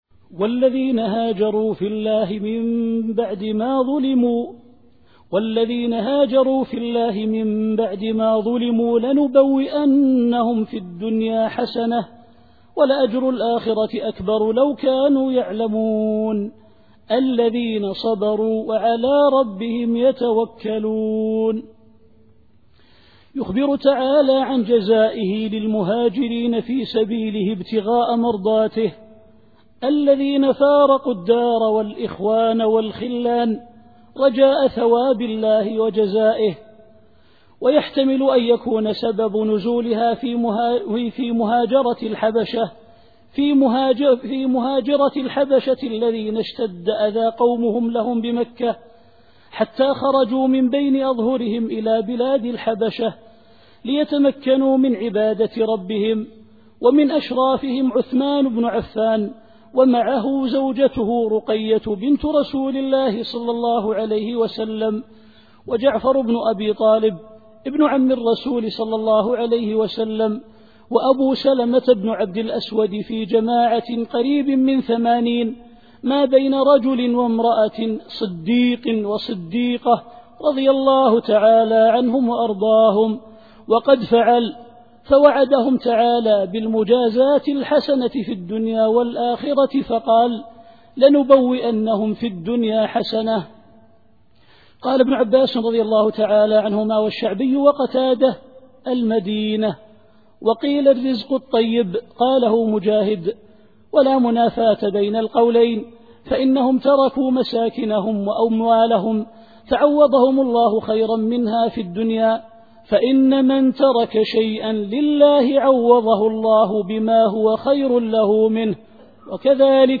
التفسير الصوتي [النحل / 41]